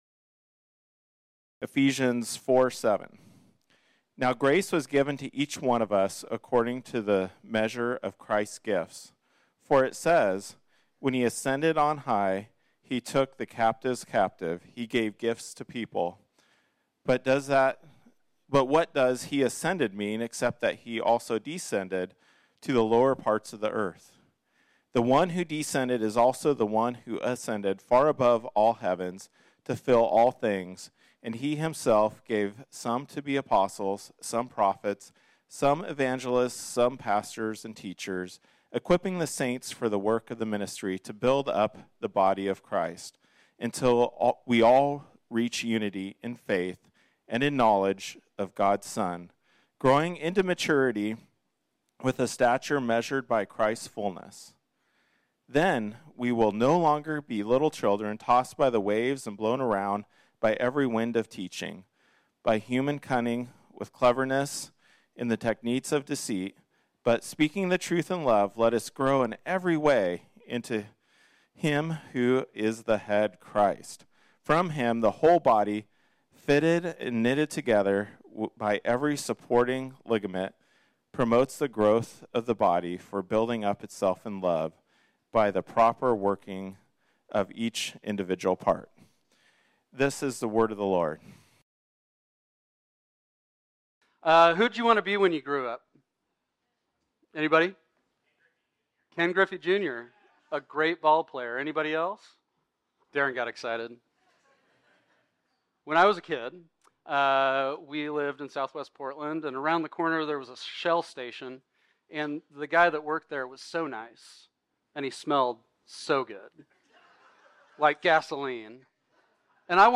This sermon was originally preached on Sunday, October 29, 2023.